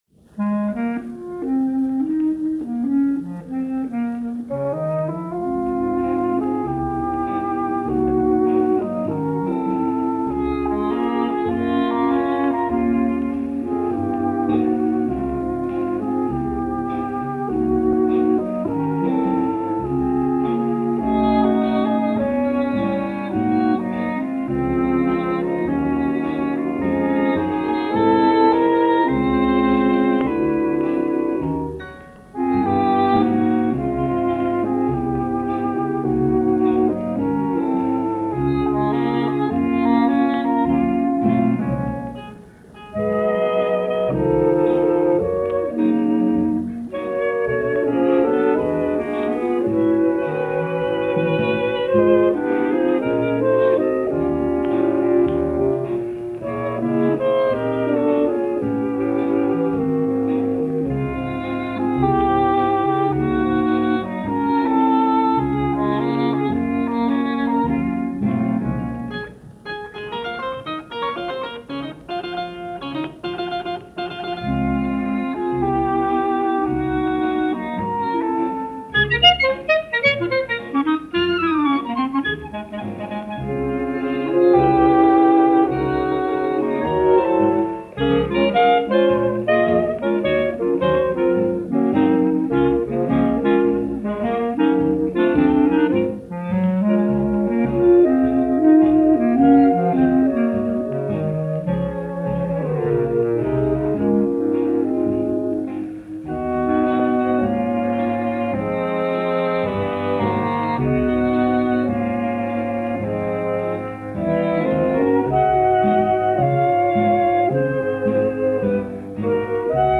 A Jazz Composer to Pop aficionados.
including a harpsichord in the mix.